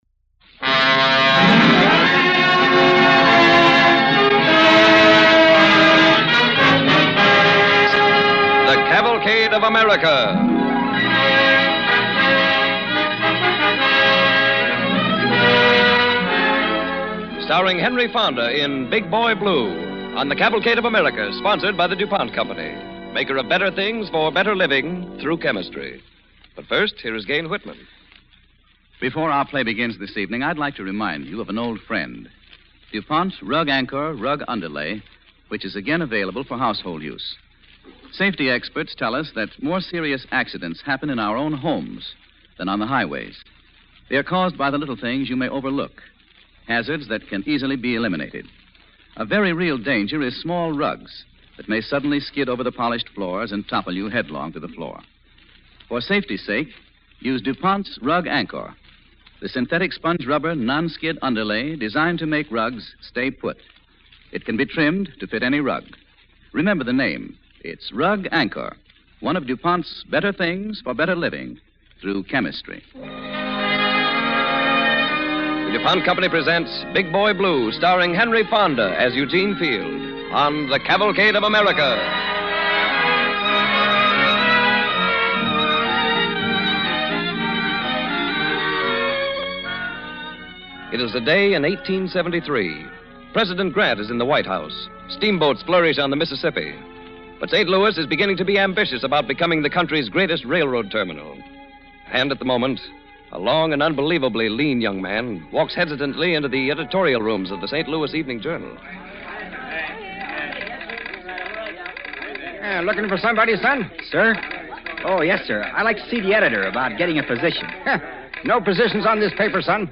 Cavalcade of America Radio Program
Big Boy Blue, starring Henry Fonda